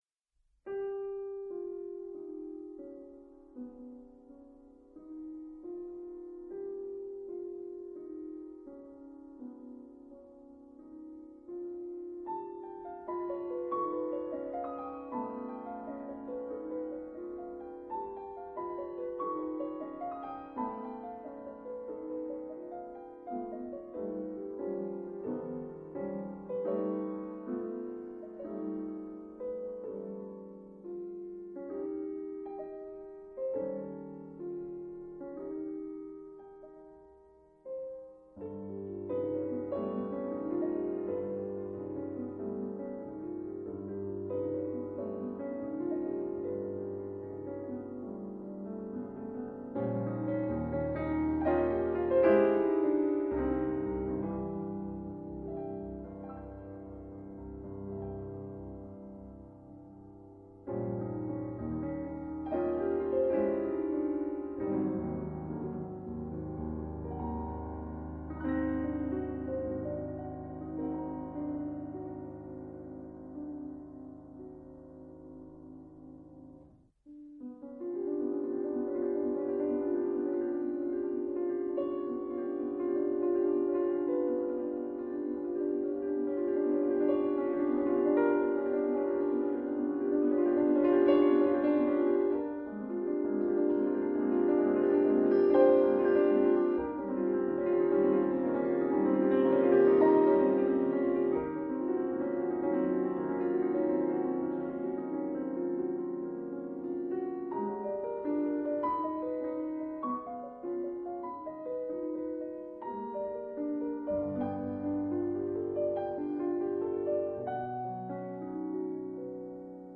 Piano
Style: Classical